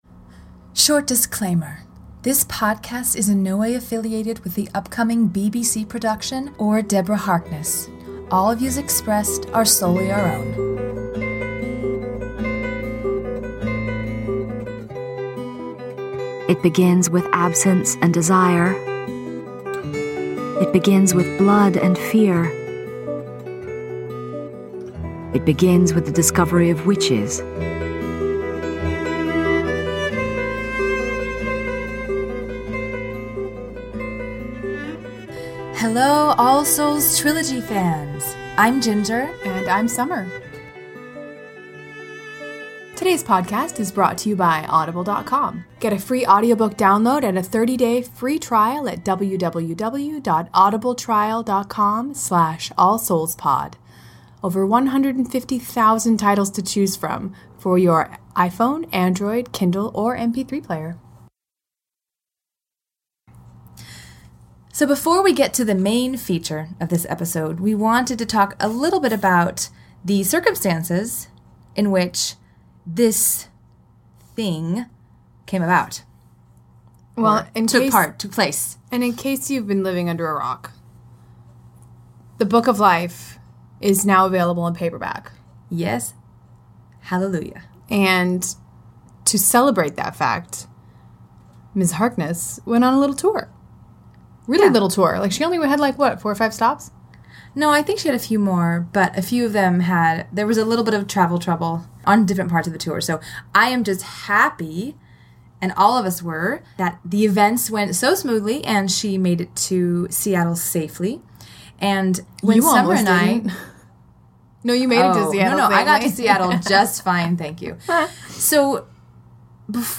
An interview with Deborah Harkness